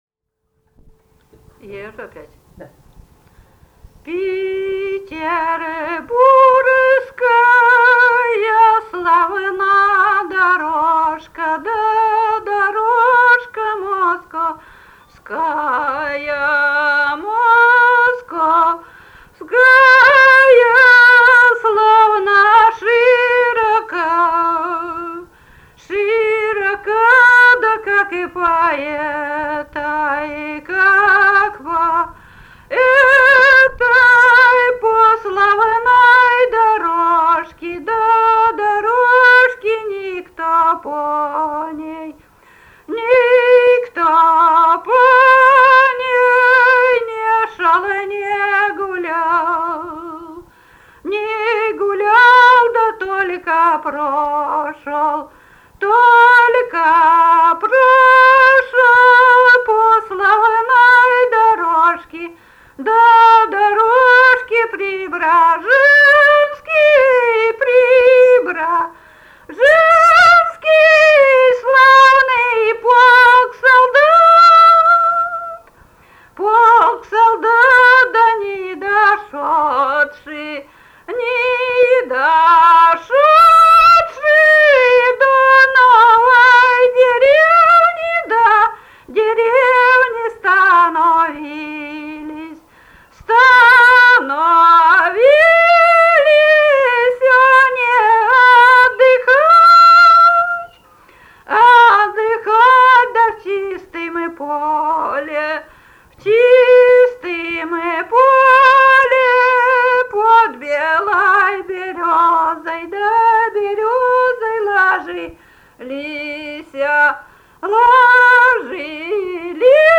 Русские народные песни Владимирской области 17а. Петербургская славна дорожка (протяжная рекрутская) с. Мстёра Вязниковского района Владимирской области.